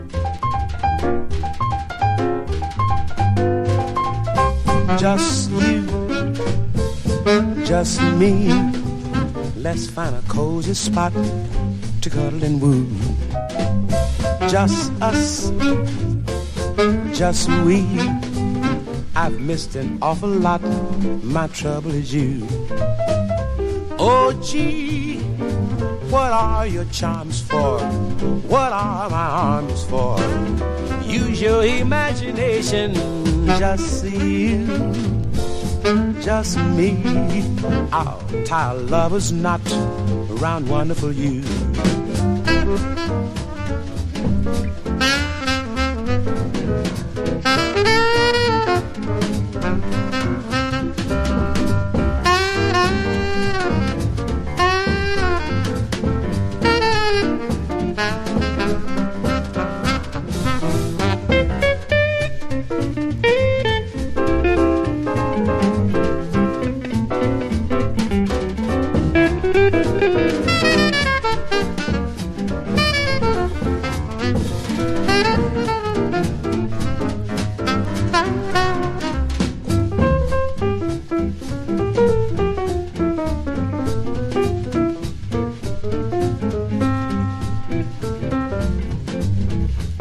ピアノ・ベース・ギターというシンプルな編成をベースにバラード〜スウィング中心のスタンダードを取り上げてます。
（1970年　MONO コーティング/フリップバック）